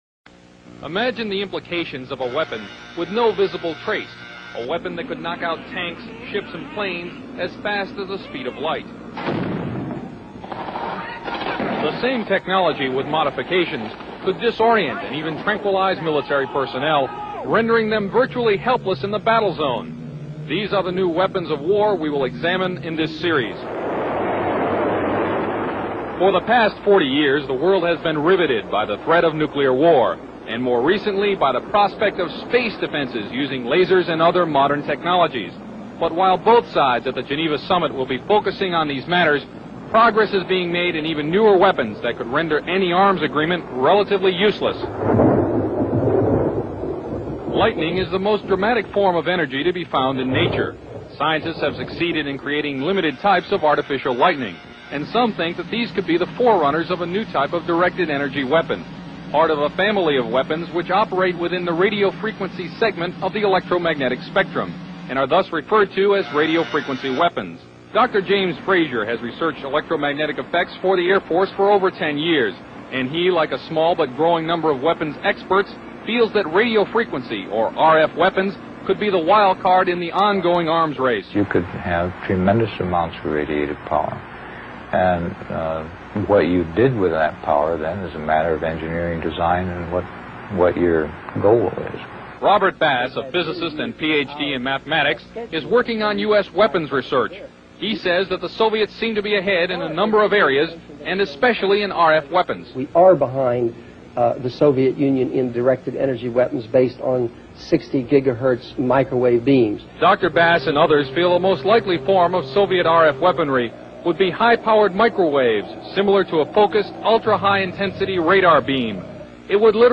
CNN Report in 1985 EMFs 60 ghz is a weapon